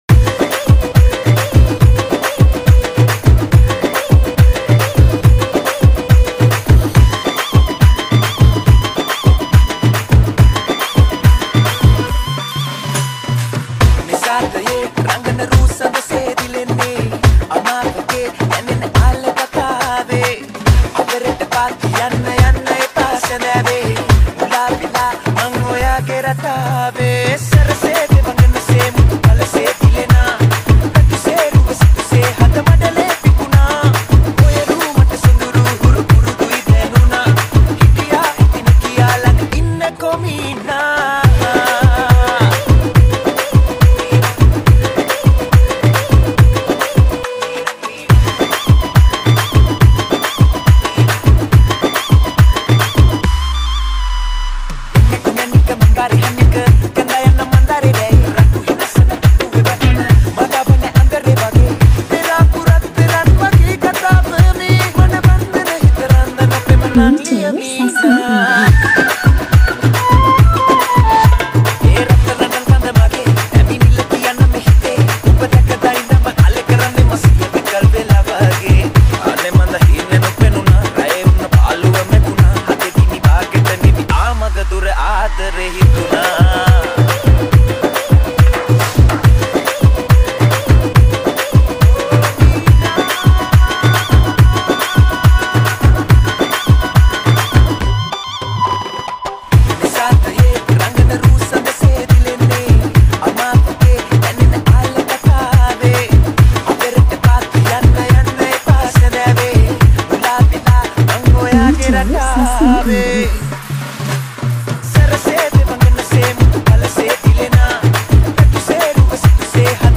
Releted Files Of Sinhala New Dj Remix Single Mp3 Songs